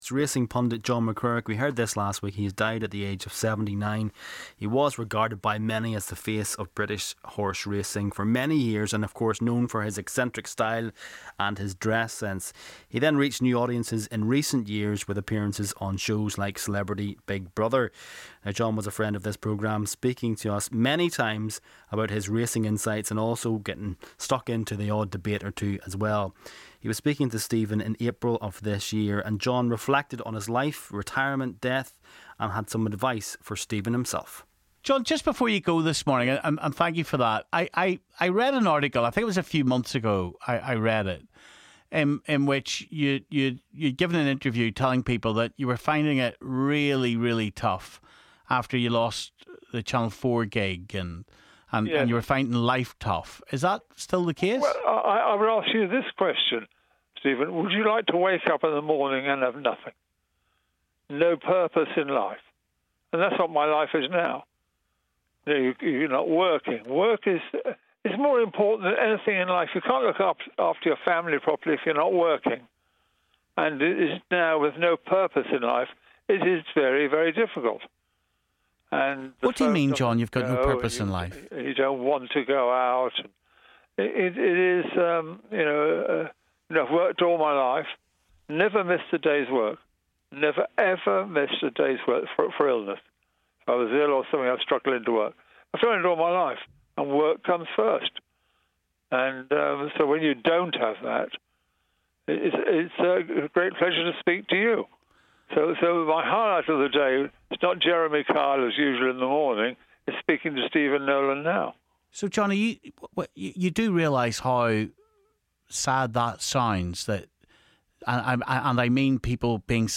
His race is run - the legend that was John McCrirrick in one of his final interviews with Stephen Nolan